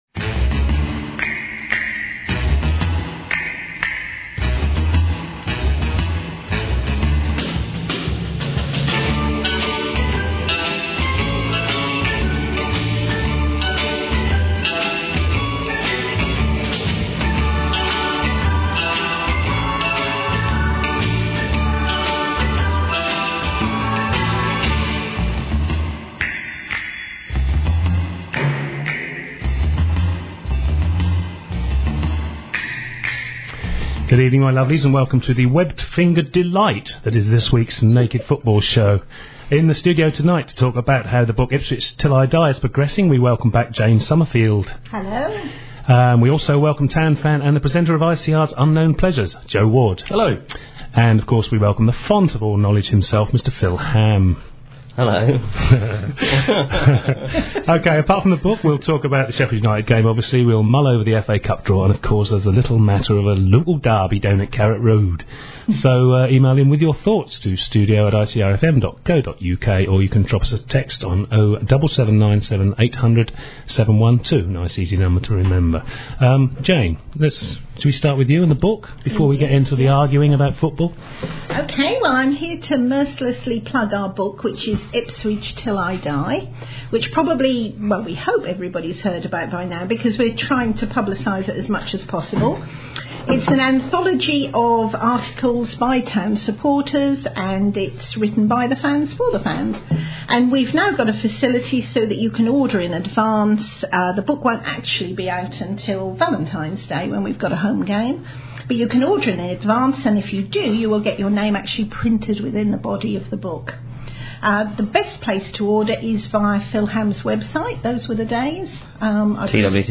The programme goes out live at 6pm every Wednesday on Ipswich Community Radio at 105.7FM if in the Ipswich area or online if not.